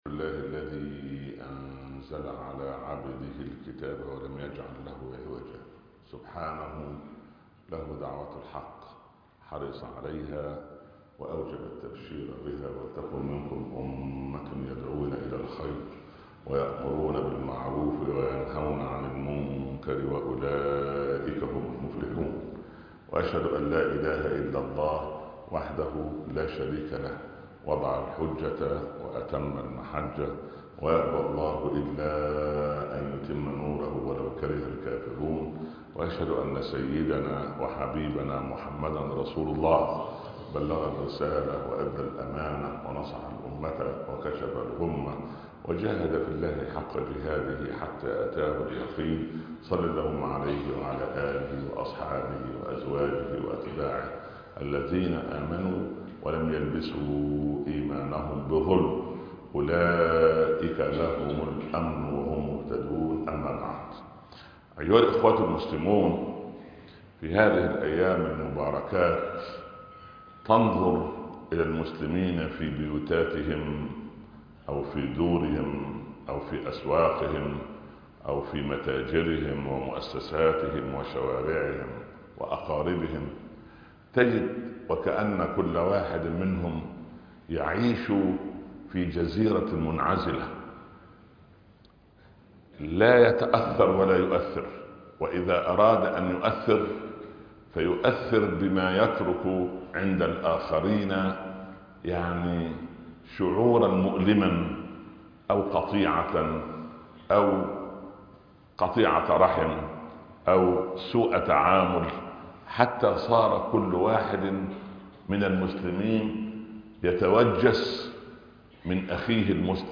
من المسلم ؟ ( خطب الجمعة